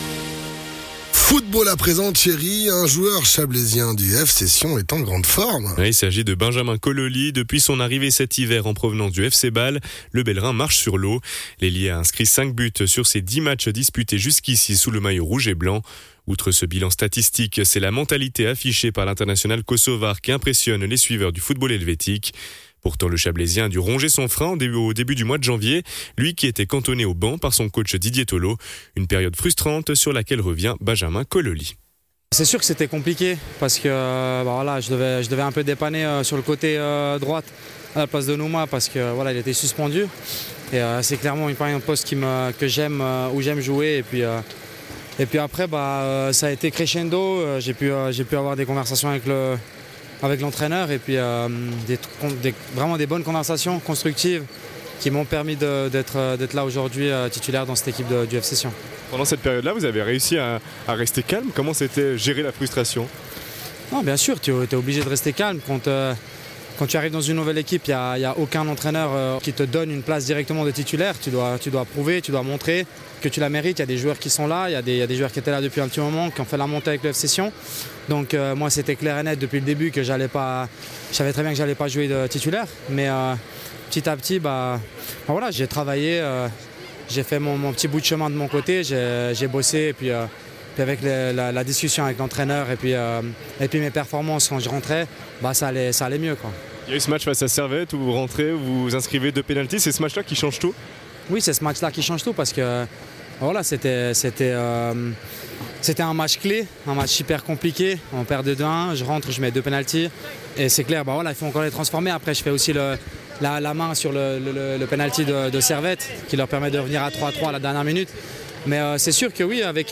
Intervenant(e) : Benjamin Kololli, footballeur